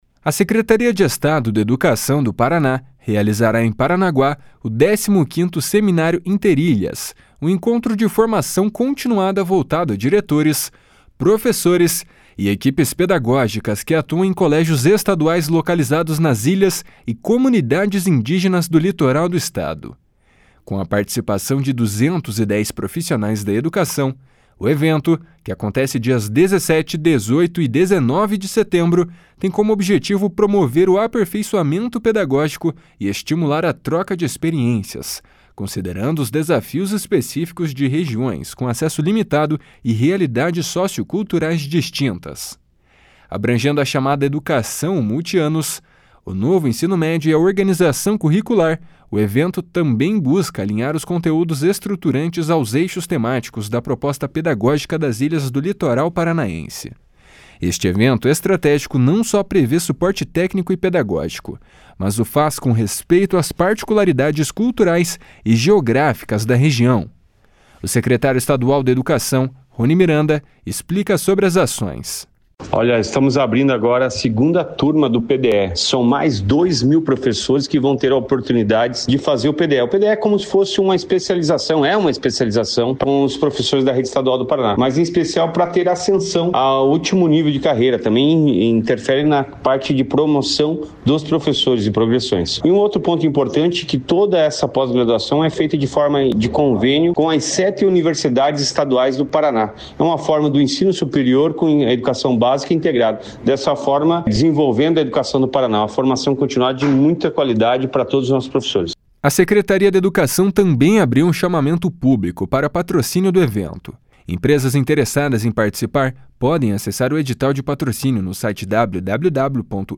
O secretário estadual de Educação, Roni Miranda, explica sobre as ações. // SONORA RONI MIRANDA //